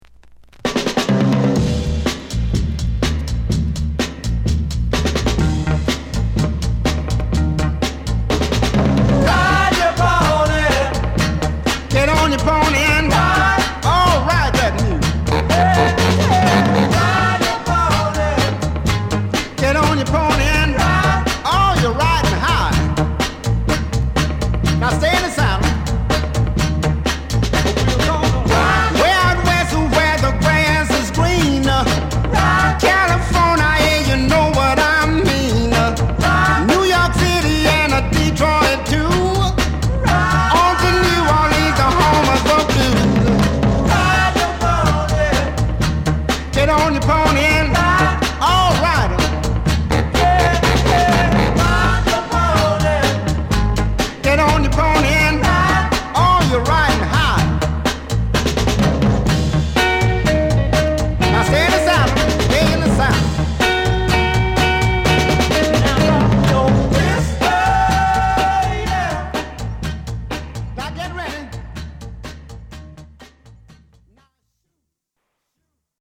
ファンキーです！！